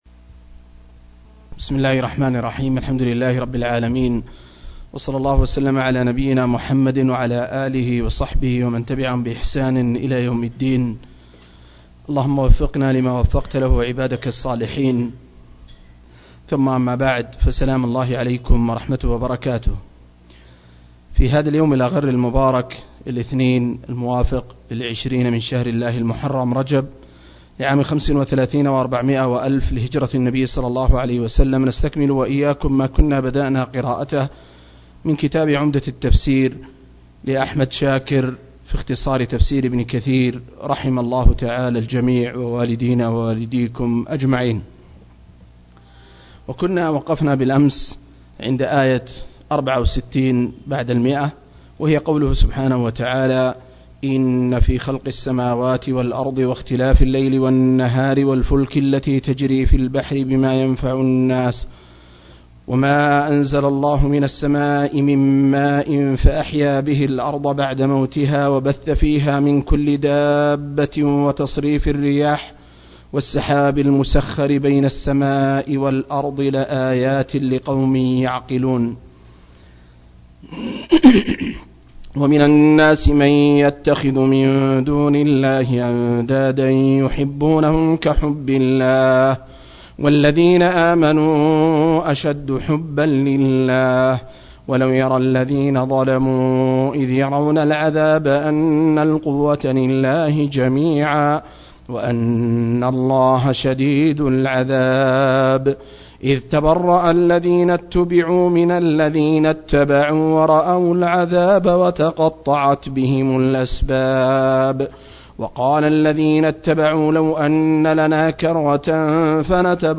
033- عمدة التفسير عن الحافظ ابن كثير – قراءة وتعليق – تفسير سورة البقرة (الآيات 169-164)